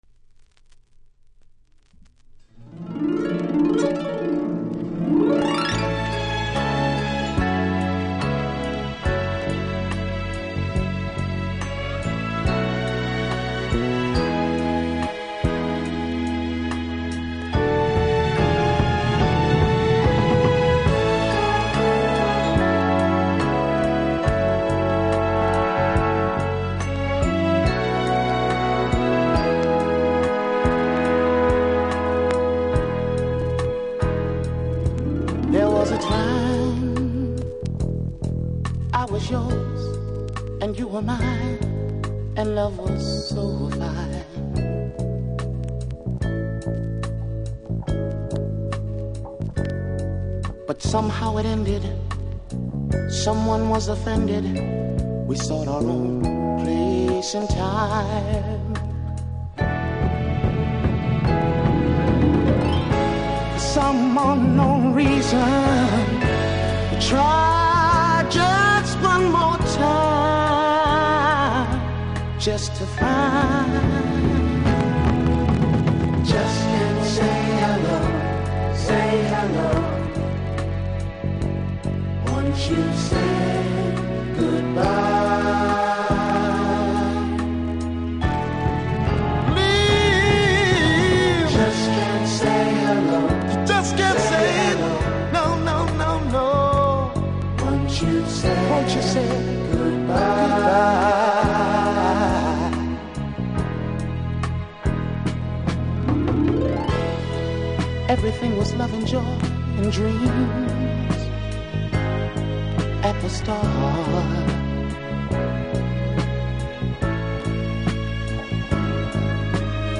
盤面綺麗ですが少しノイズ感じますので試聴で確認下さい。